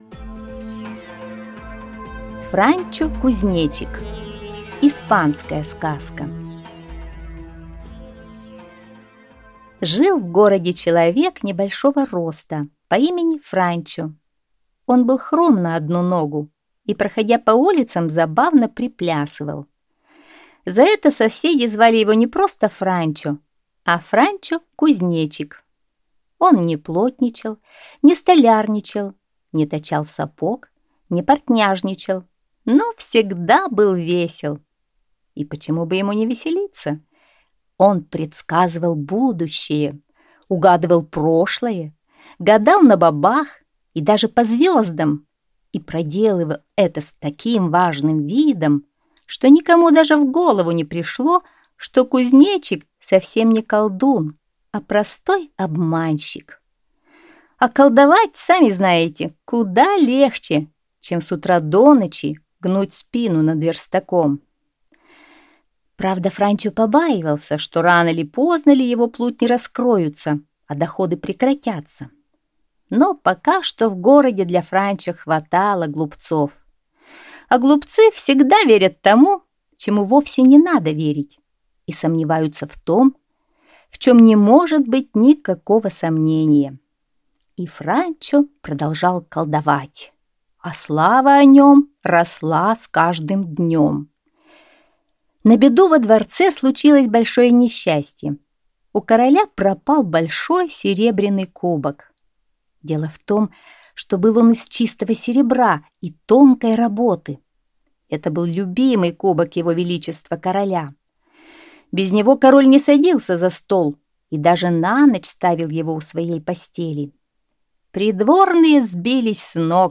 Франчо-Кузнечик - испанская аудиосказка - слушать онлайн